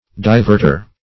Diverter \Di*vert"er\, n.